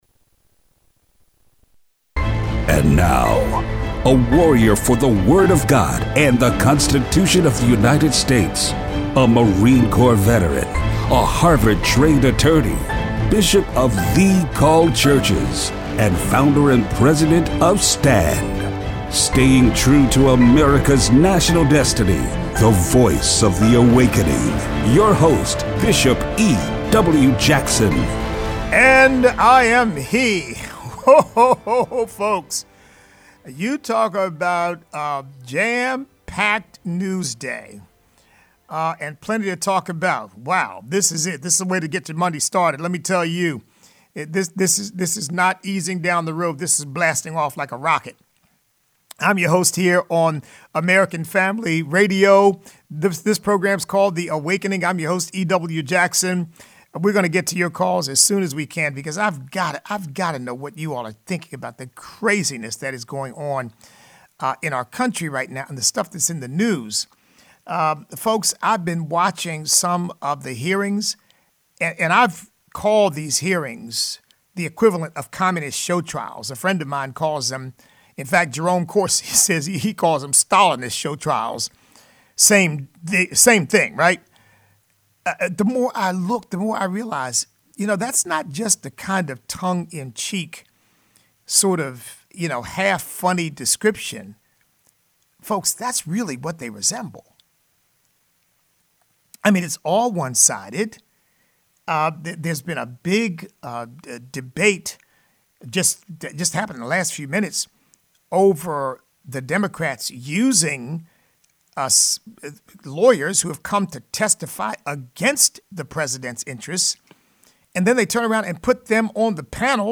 Listener call-in.